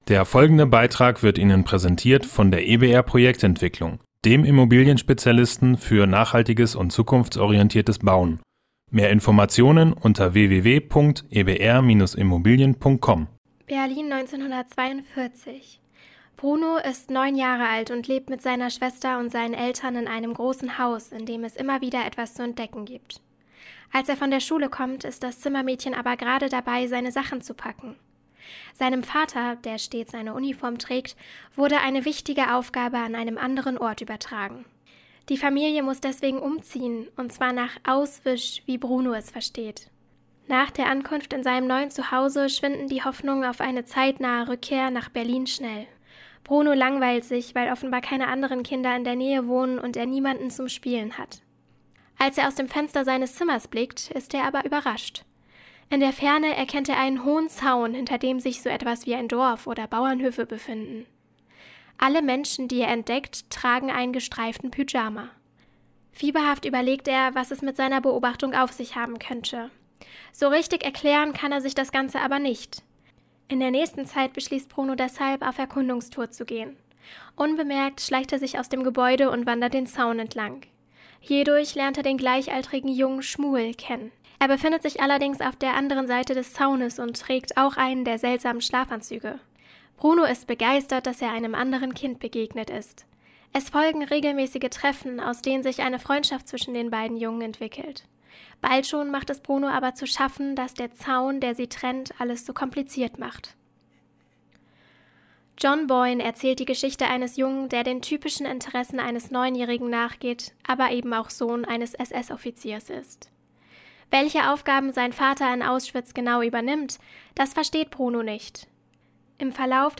Beiträge > Rezension: Der Junge im gestreiften Pyjama - StadtRadio Göttingen